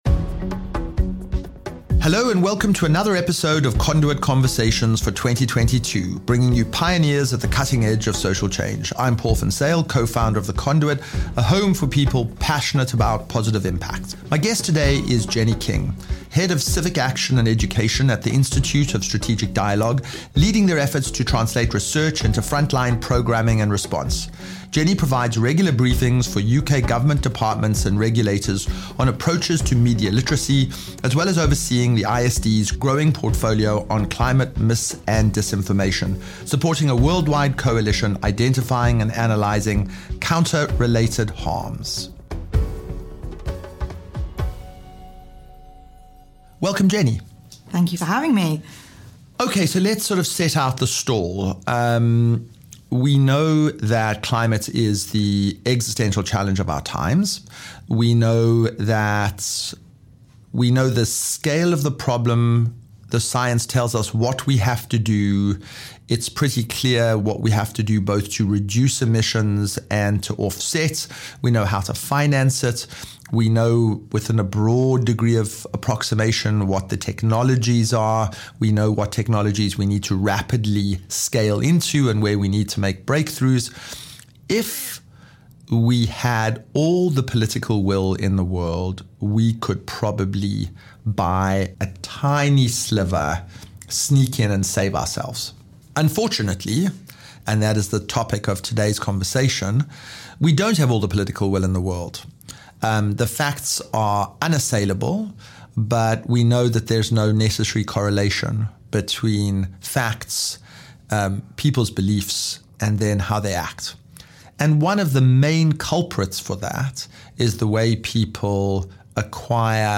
This is a fascinating and timely conversation delving deep into the murky world of Mis and Disinformation and its impact on making positive forward momentum against climate change.